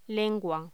Locución: Lengua